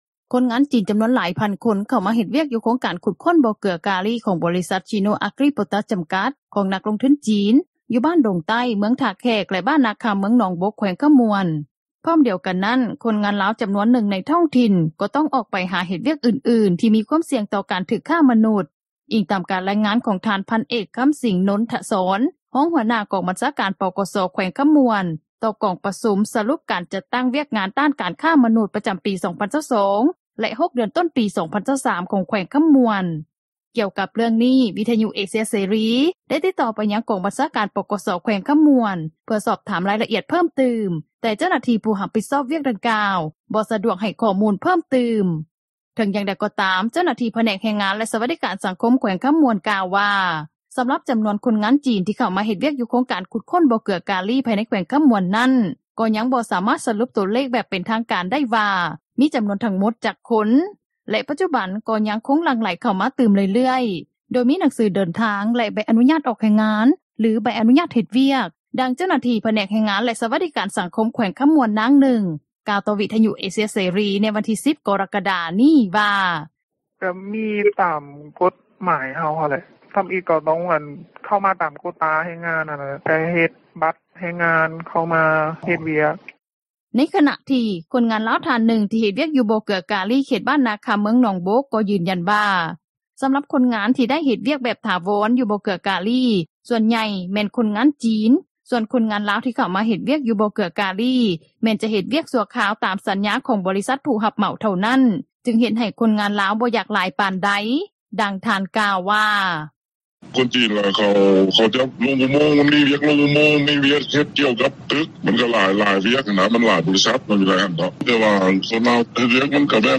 ດັ່ງເຈົ້າໜ້າທີ່ ຜແນກແຮງງານ ແລະ ສວັດດີການສັງຄົມ ແຂວງຄໍາມ່ວນ ນາງນຶ່ງ ກ່າວຕໍ່ວິທຍຸເອເຊັຽເສຣີ ໃນວັນທີ 10 ກໍຣະກະດາ ນີ້ວ່າ: